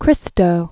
(krĭstō) Originally Christo Vladimirov Javacheff.